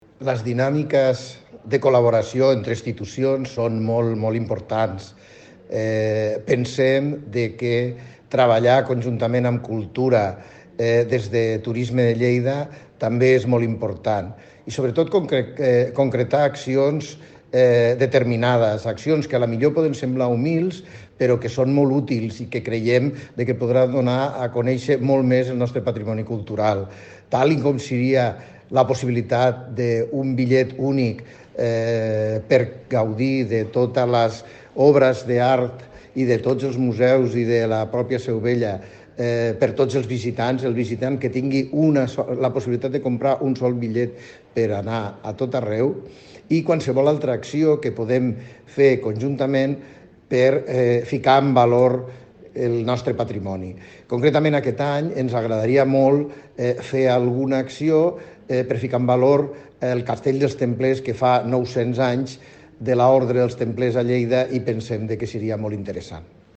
tall-de-veu-paco-cerda